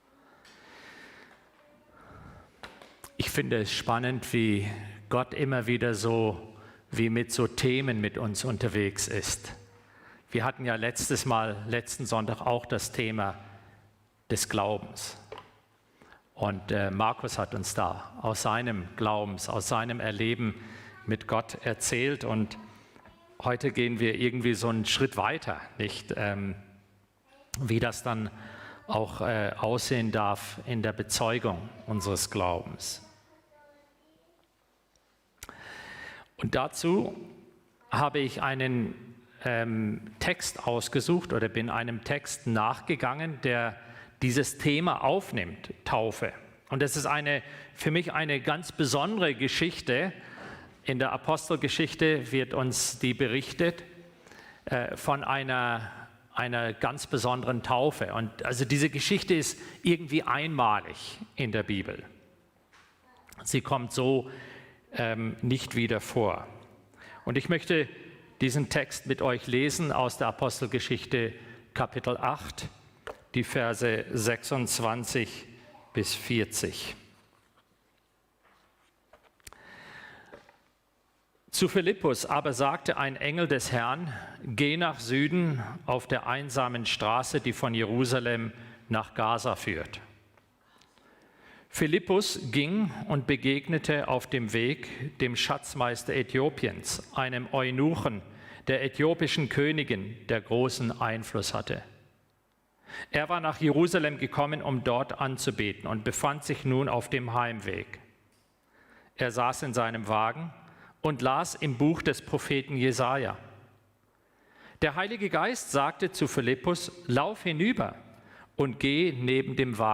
Gottesdienst_-Glaube-und-Taufe.mp3